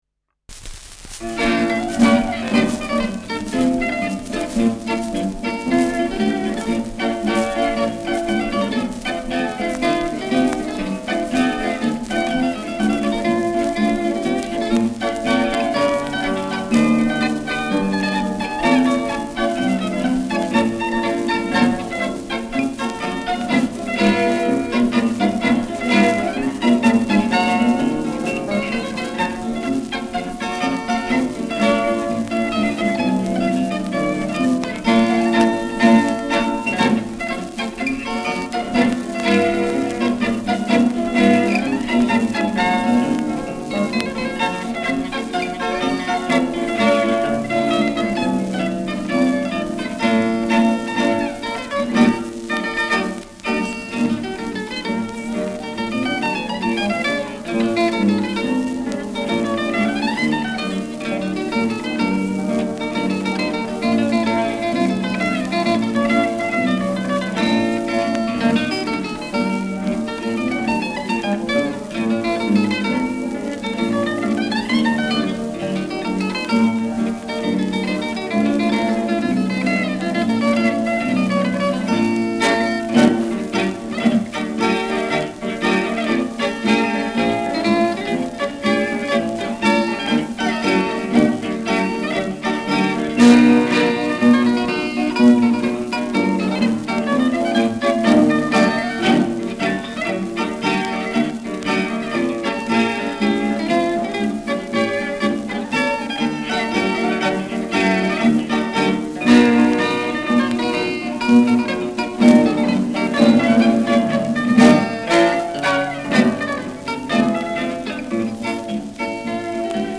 Schellackplatte
leichtes Rauschen : leichtes Knistern
[München] (Aufnahmeort)